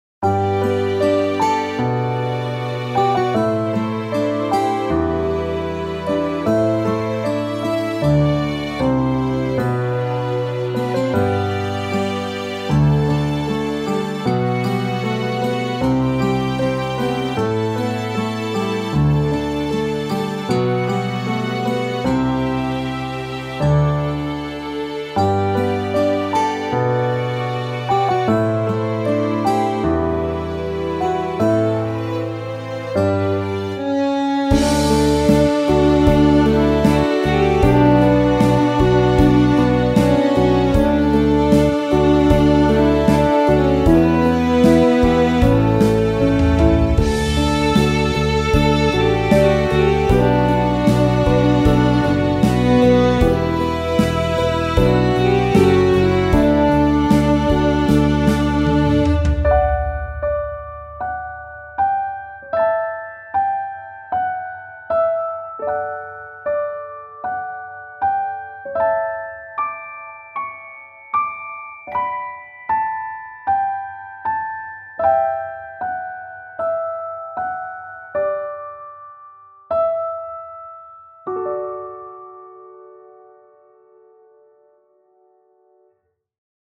BGM ピアノ バイオリン バラード リラックス 静か 優しい 星 癒し 落ち着く 感動 穏やか 夜